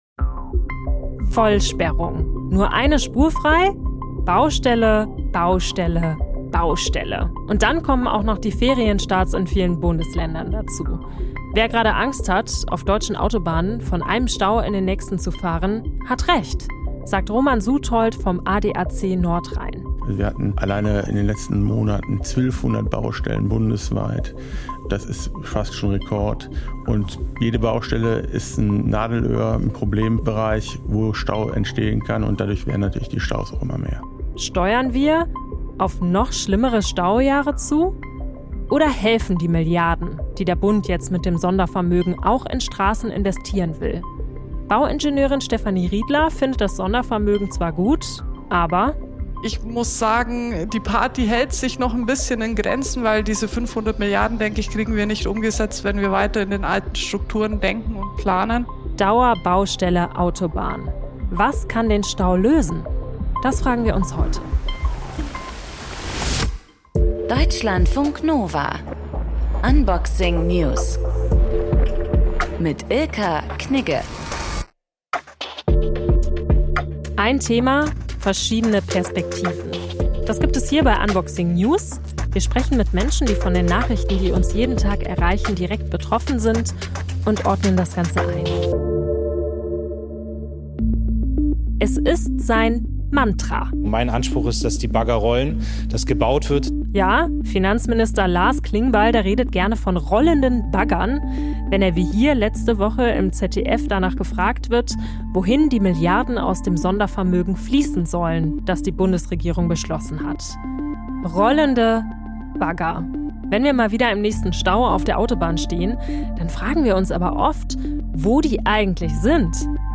Das Interview im Deutschlandfunk Kultur greift kulturelle und politische Trends ebenso auf wie... Mehr anzeigen